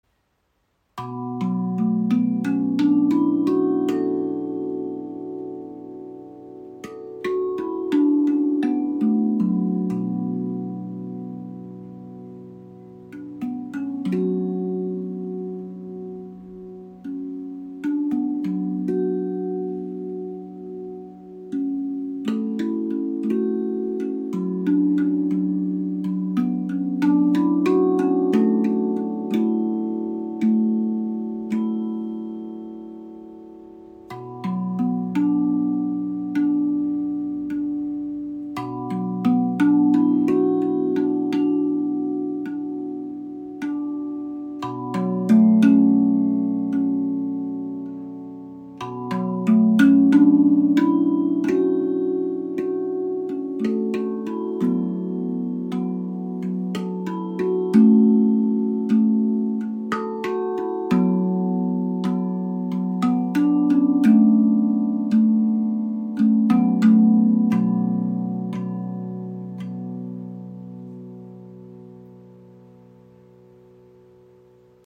Erlebe die ARTQUINT Hybrid Drum – eine harmonische Verbindung aus Handpan und Zungentrommel. Warmer Klang, edles Design, verschiedene Stimmungen.
Klangbeispiel
Die B Celtic Minor ist wie ein sanfter Klangteppich, der sich unter Deine Hände legt. Mit ihrer warmen, leicht melancholischen Stimmung öffnet sie einen Raum der Ruhe, in dem Du tief durchatmen und Dich mit Dir selbst verbinden kannst.
Sanfter, lang nachschwingender Klang – vergleichbar mit einer Handpan